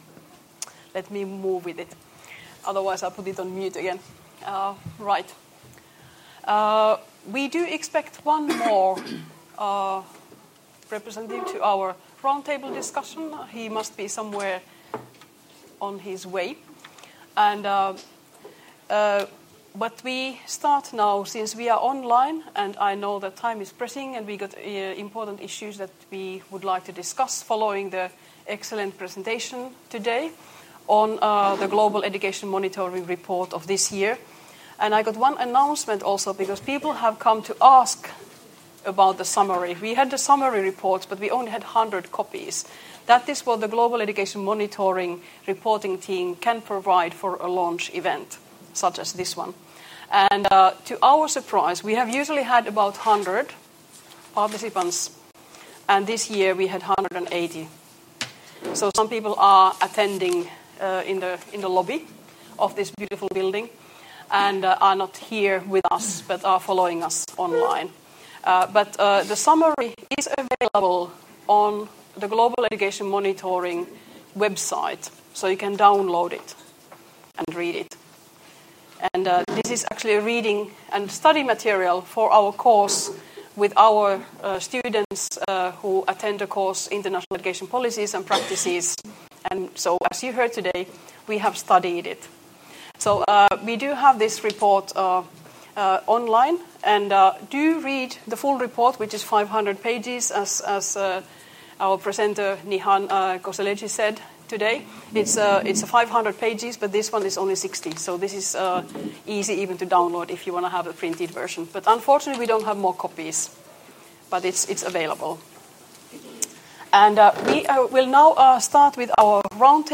Roundtable discussion: Our roles and responsibilities — Moniviestin